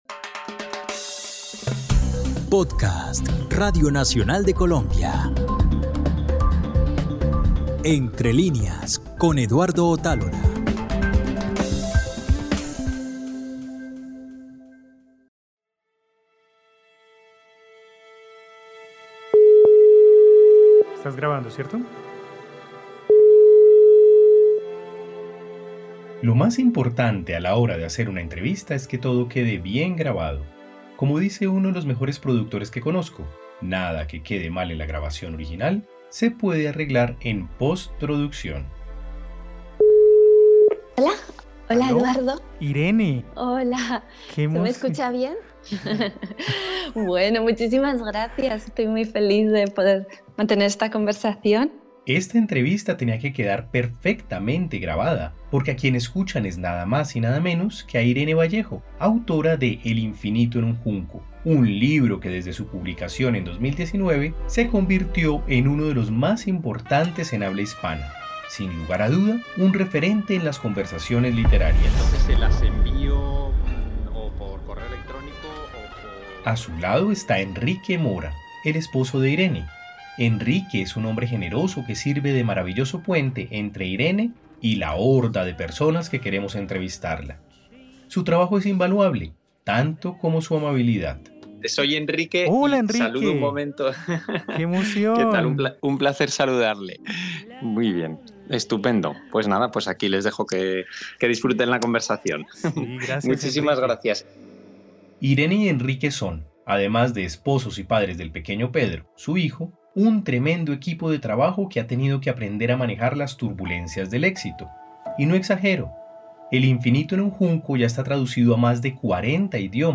Entrevista a la escritora española Irene Vallejo